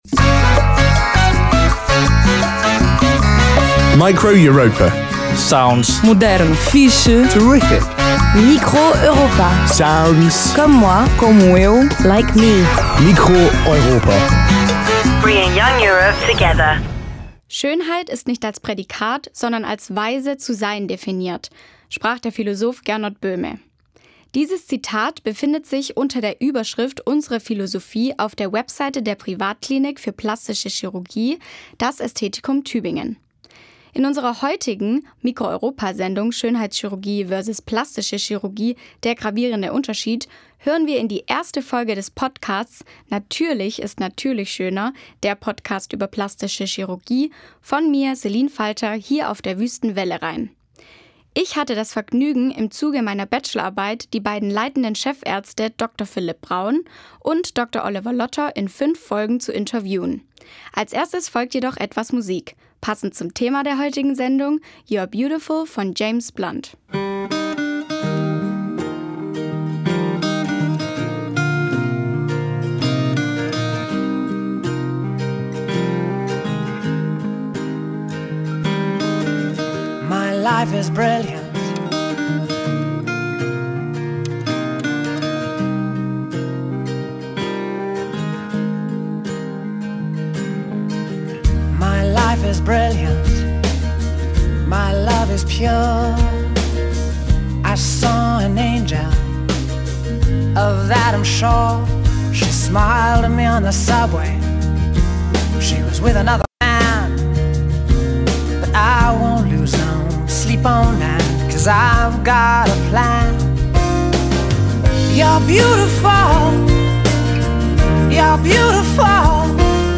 2024-11-12 Form: Live-Aufzeichnung,